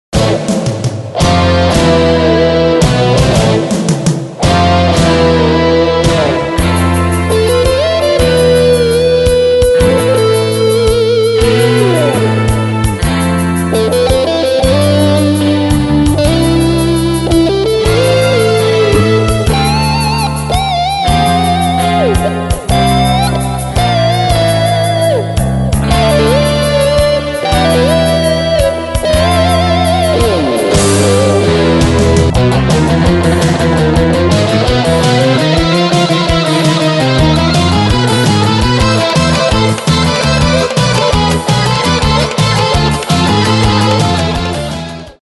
10 instrumentala